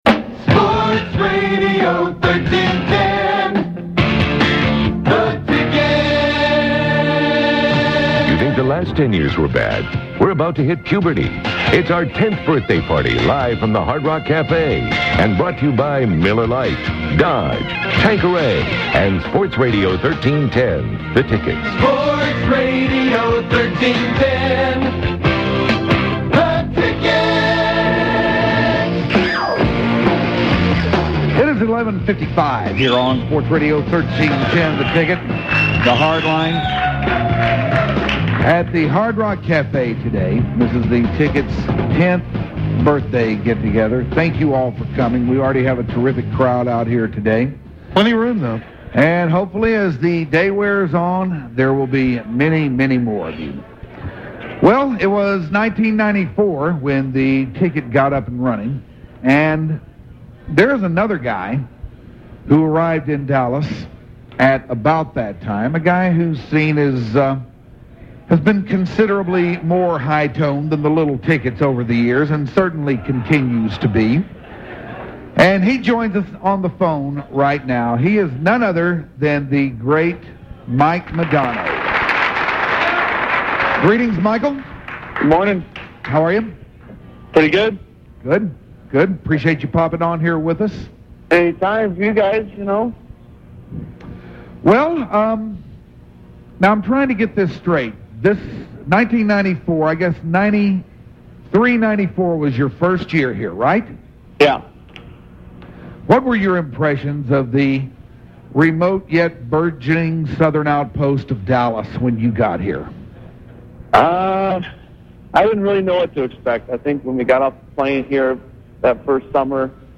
10th Anniversary Party (Part 4) - The UnTicket
The Hardline talks to Dallas Stars great Mike Modano